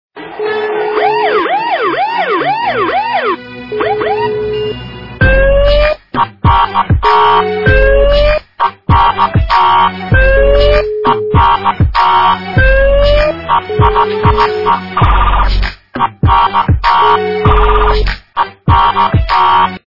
» Звуки » Смішні » Супер-звонок - Спецсигнал
При прослушивании Супер-звонок - Спецсигнал качество понижено и присутствуют гудки.
Звук Супер-звонок - Спецсигнал